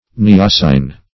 Search Result for " neossine" : The Collaborative International Dictionary of English v.0.48: Neossine \Ne*os"sine\, n. [Gr. neossia` a bird's nest.]